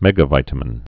(mĕgə-vītə-mĭn)